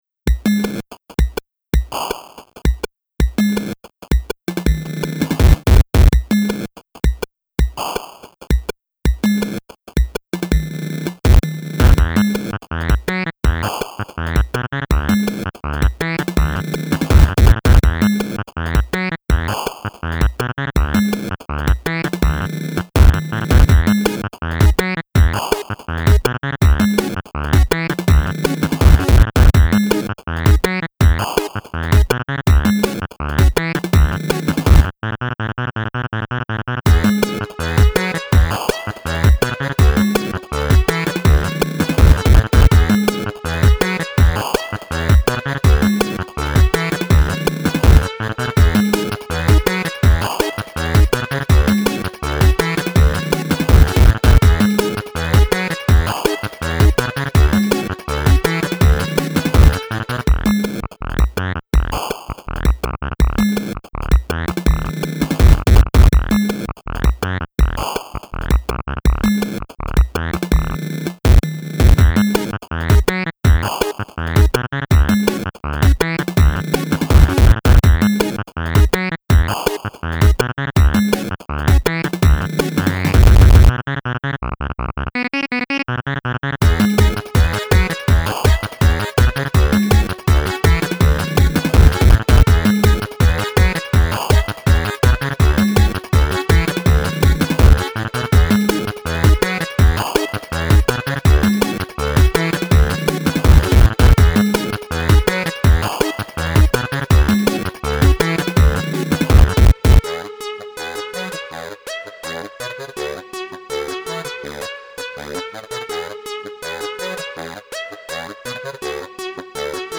Nervy restless 8-bit electronica from quirkyland.